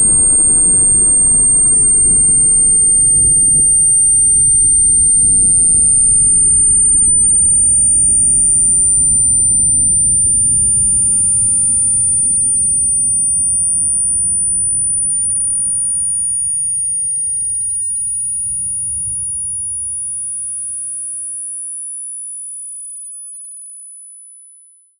Ported the ACE2 ear ringing logic
backblast_ring.ogg